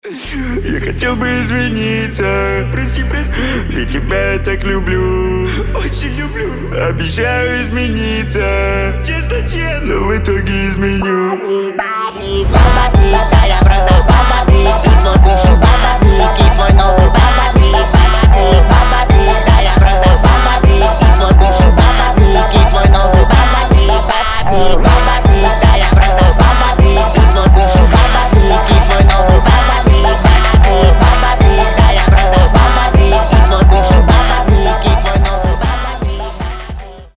Рэп рингтоны , Jersey club